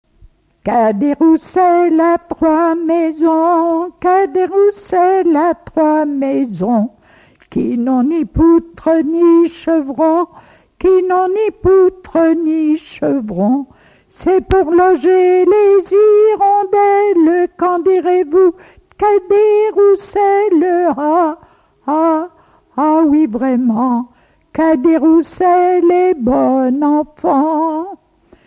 enfantine : lettrée d'école
Pièce musicale inédite